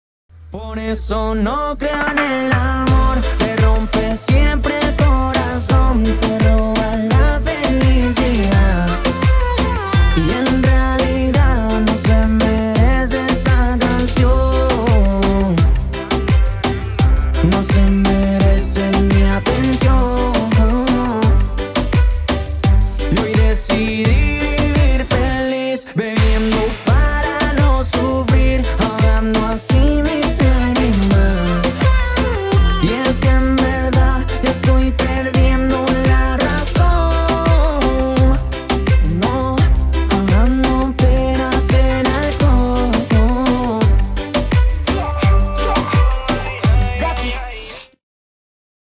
• Качество: 320, Stereo
латинские
Reggaeton